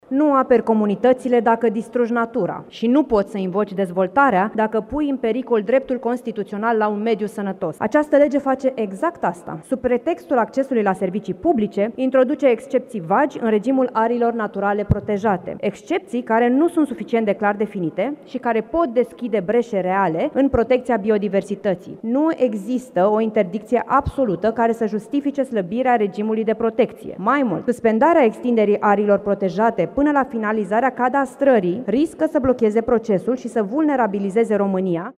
Senatoarea USR Cynthia Păun: „Nu poți să invoci dezvoltarea dacă pui în pericol dreptul constituțional la un mediu sănătos”